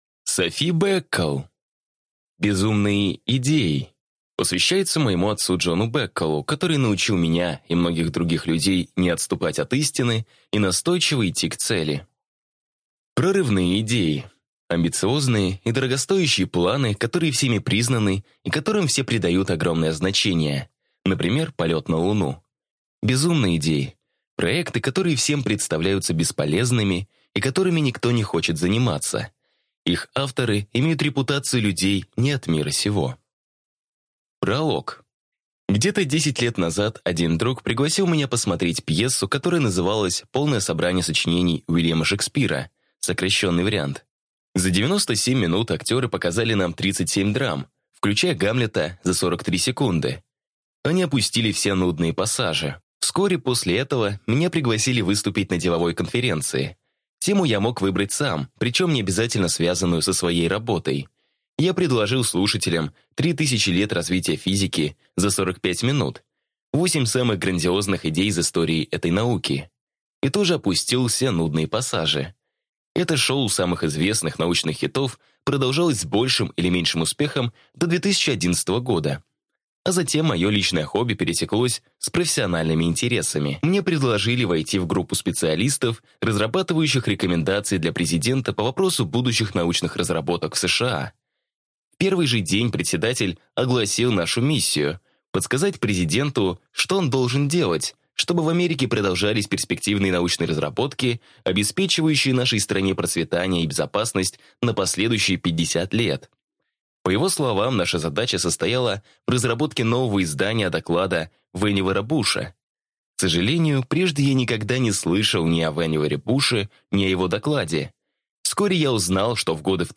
Аудиокнига Безумные идеи: как не упустить кажущиеся бредовыми идеи, способные выигрывать войны, искоренять болезни и менять целые отрасли | Библиотека аудиокниг